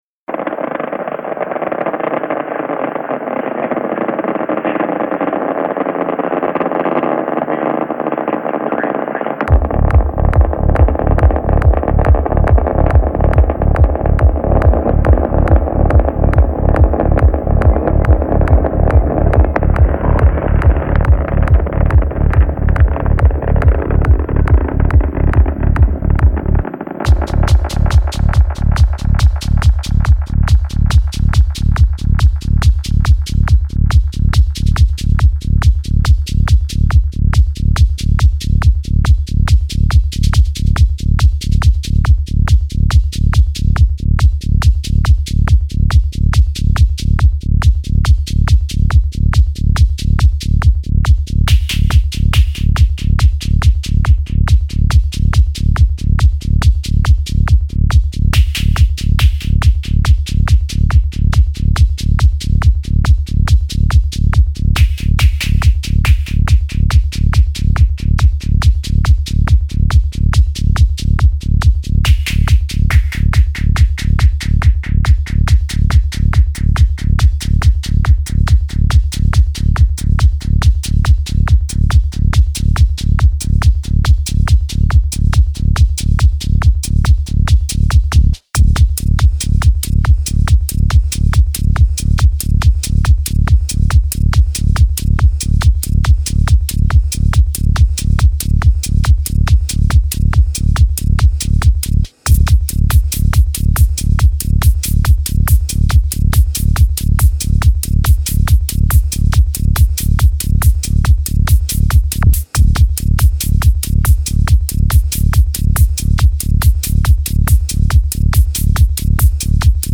Жанр: TECHNO